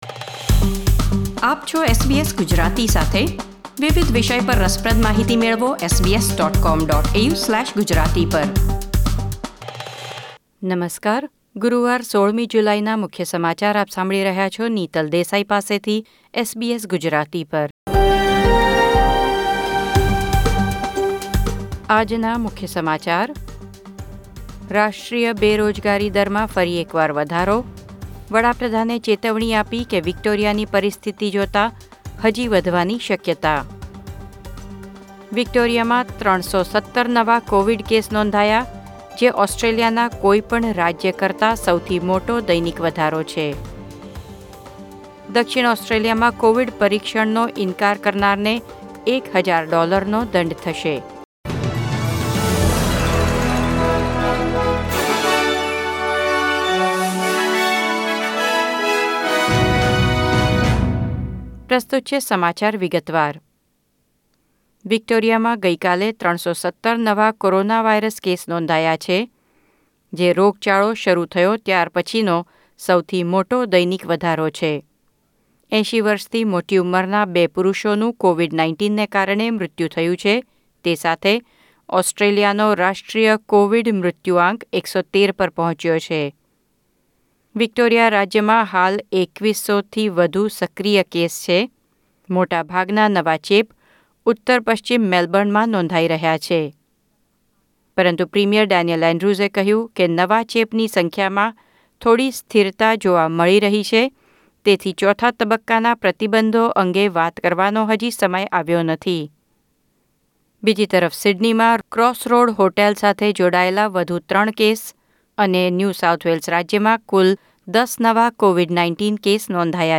SBS Gujarati News Bulletin 16 July 2020